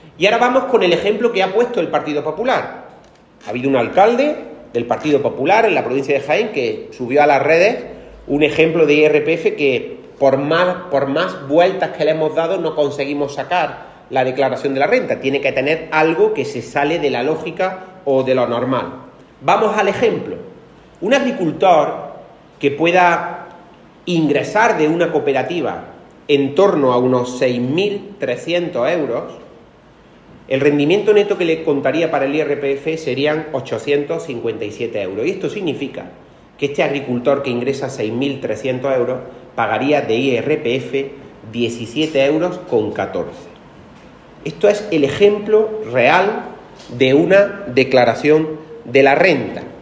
En rueda de prensa, Latorre puso ejemplos concretos que demuestran su afirmación y que contradicen los bulos propagados por el PP y alguno de sus alcaldes.
Cortes de sonido